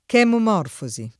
vai all'elenco alfabetico delle voci ingrandisci il carattere 100% rimpicciolisci il carattere stampa invia tramite posta elettronica codividi su Facebook chemomorfosi [ k H momorf 0@ i ; alla greca k H mom 0 rfo @ i ] s. f. (bot.)